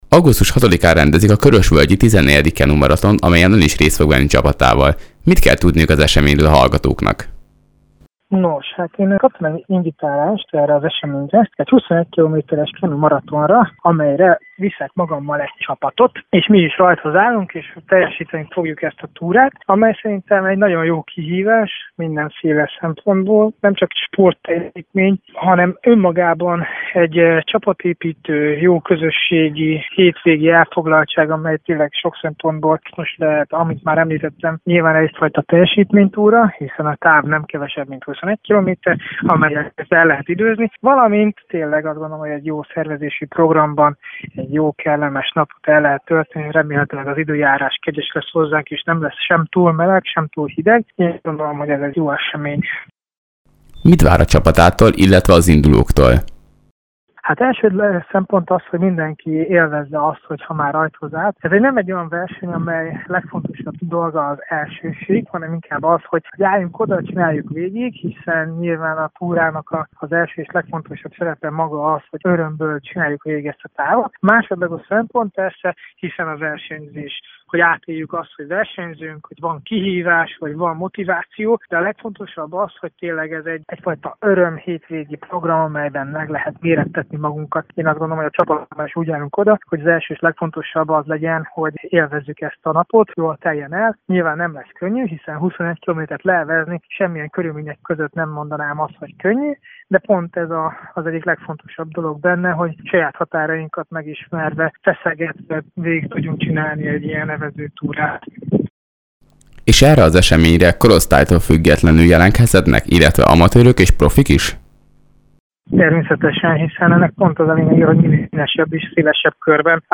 Idén is megrendezik a Körösvölgyi Kenumaratont Szarvason. A 21 km-es távon Vajda Attila olimpiai bajnok is részt vesz csapatával. Erről valamint az idei olimpiáról beszélgetett vele tudósítónk.